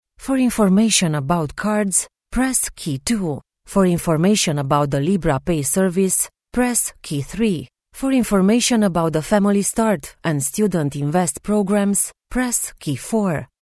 Naturelle, Accessible, Fiable, Commerciale, Corporative
Téléphonie
Thanks to her extensive career in broadcasting, her voice has been trained for reliable authenticity and clarity.